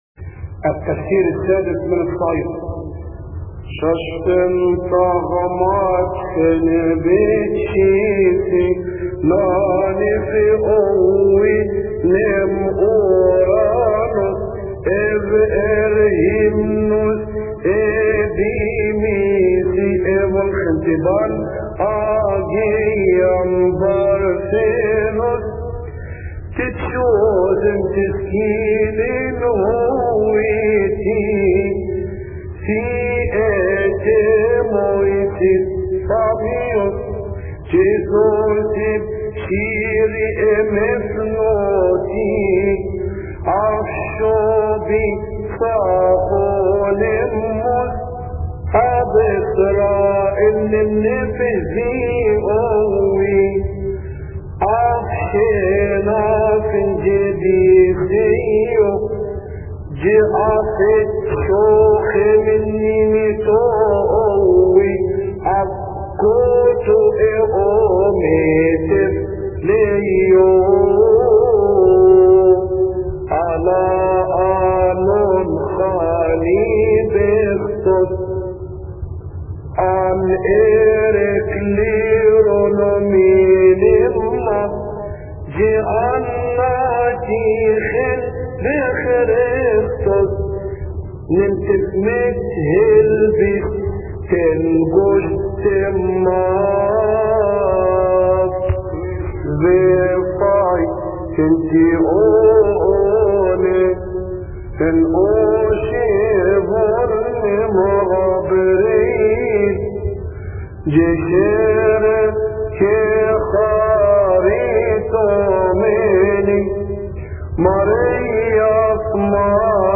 التفسير السادس من الصعيدي لثيؤطوكية السبت يصلي في تسبحة عشية أحاد شهر كيهك